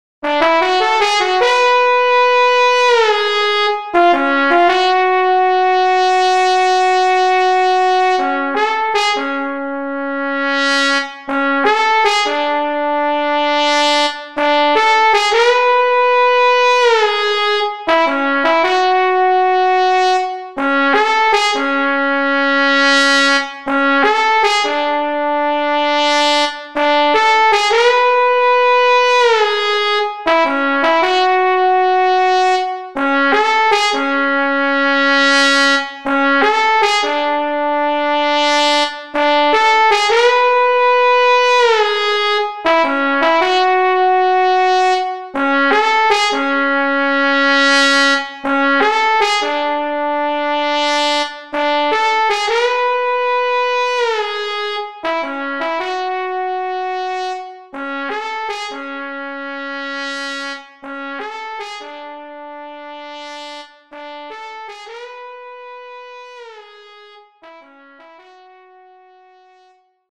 HALion6 : trombone
Trombone Hard Sfz